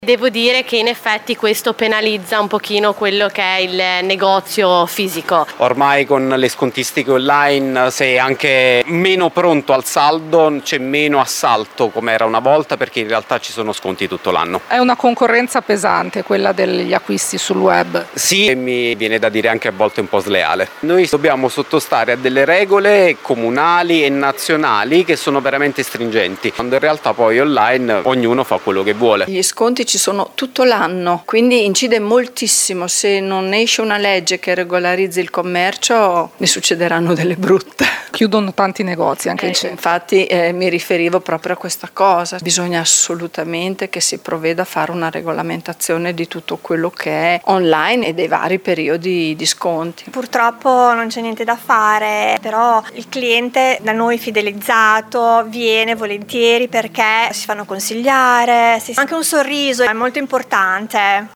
Qui sotto alcune interviste ai commercianti modenesi